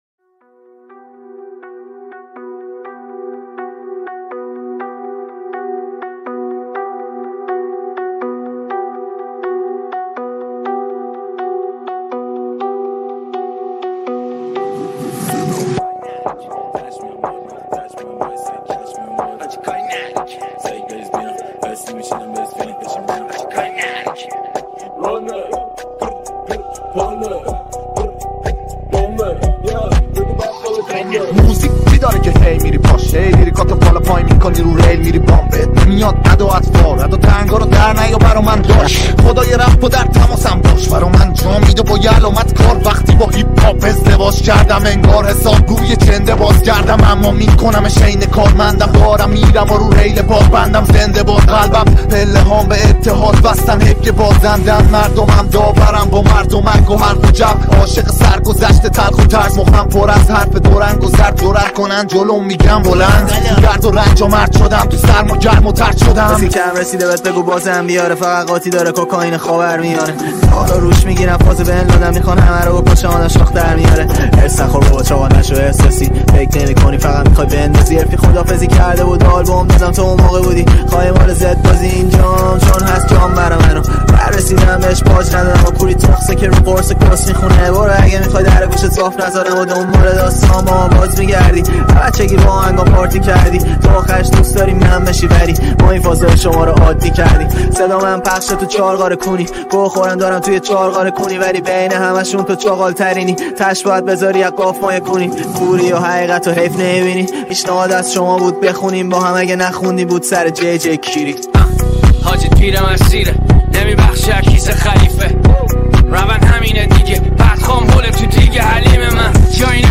هیپ هاپ